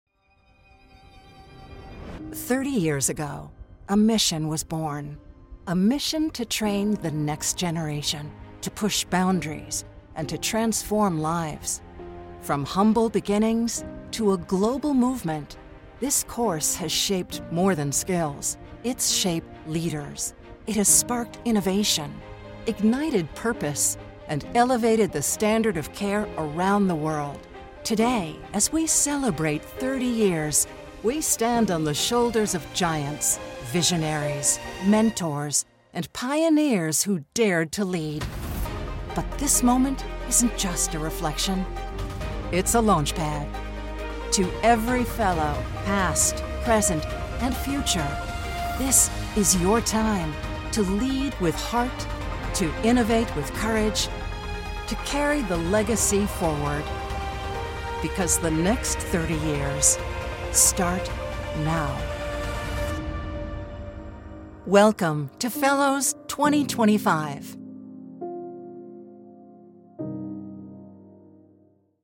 A Narrator with a Warm, Caring & Smooth Tone